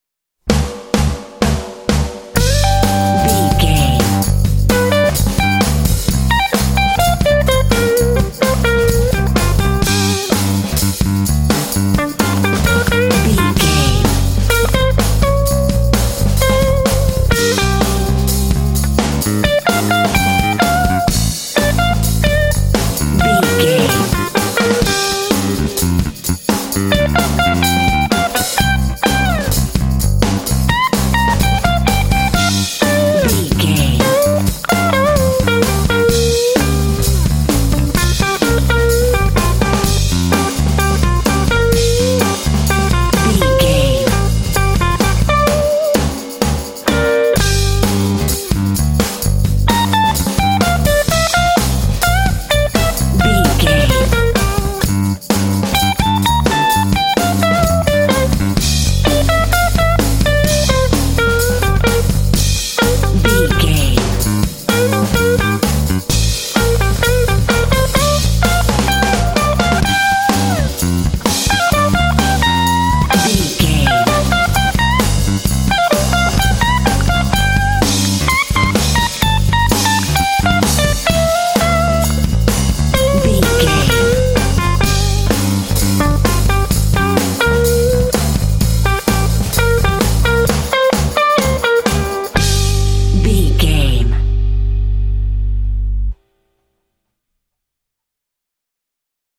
Aeolian/Minor
intense
driving
energetic
groovy
funky
electric guitar
bass guitar
drums
Funk
blues